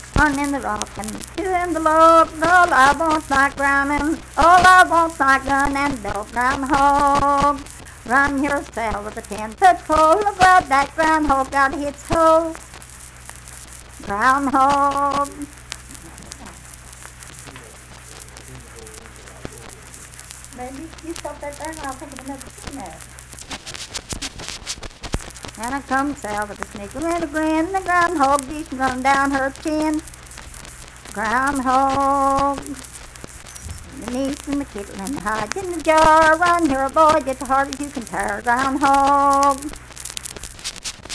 Unaccompanied vocal music performance
Verse-refrain 4(3).
Dance, Game, and Party Songs
Voice (sung)